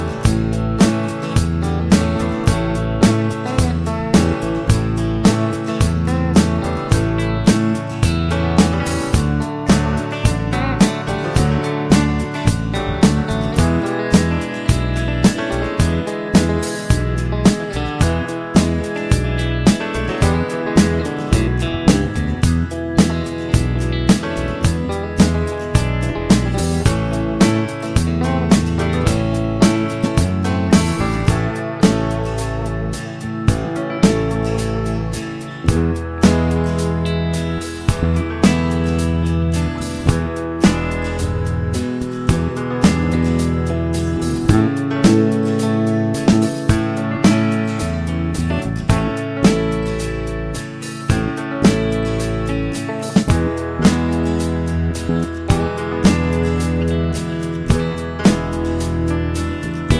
Country (Male)
Irish Backing Tracks for St Patrick's Day